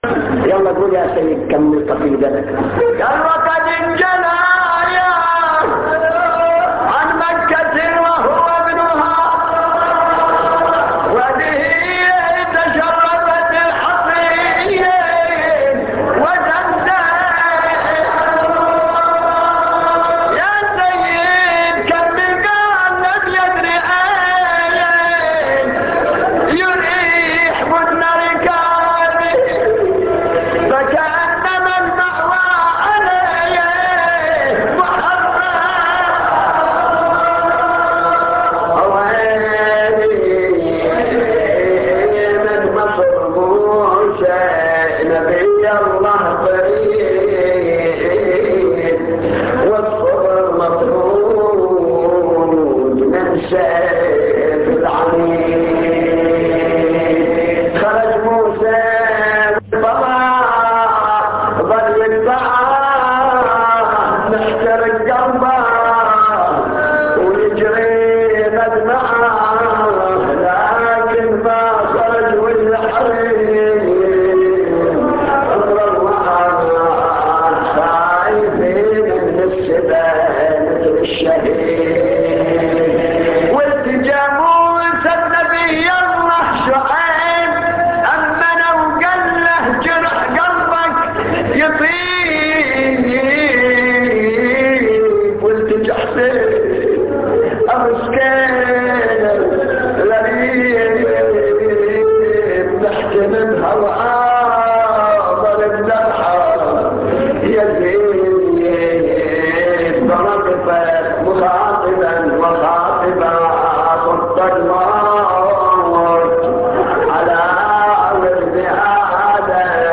نواعي حسينية 11